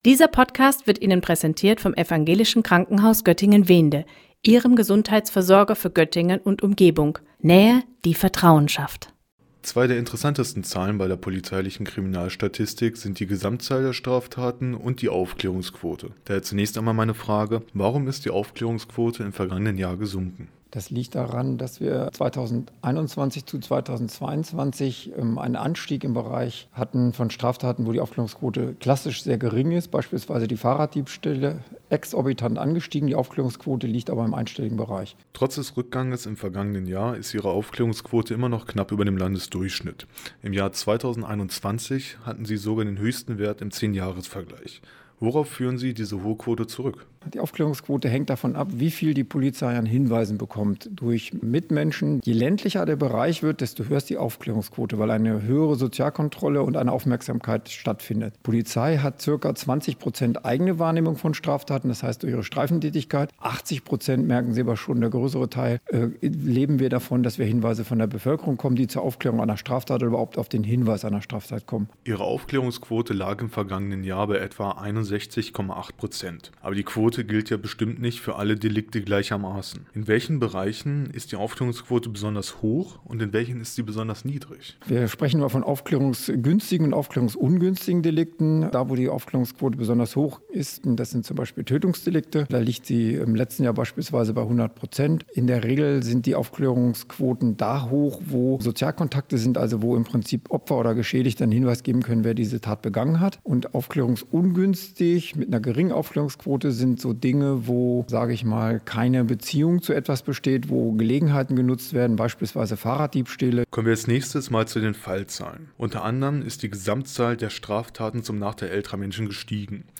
2023XXXX_Interview_Kriminalstatistik-playout.mp3